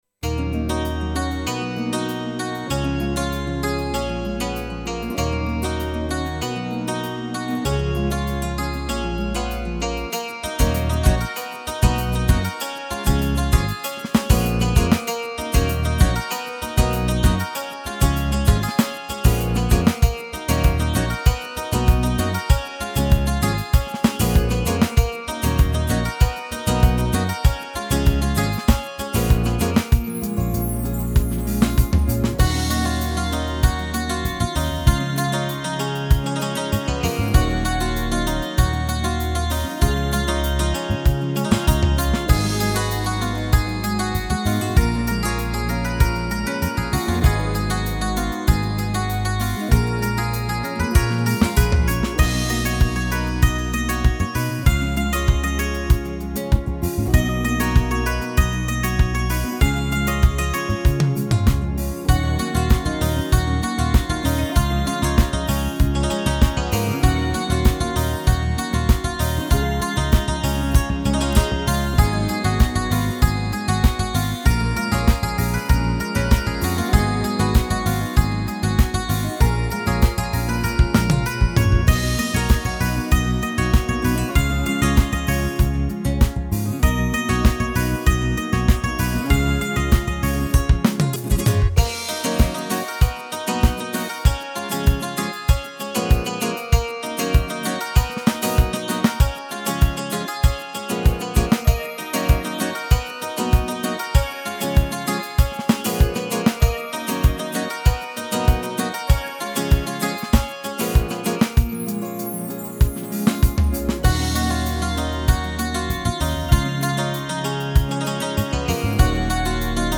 سبک موسیقی بی کلام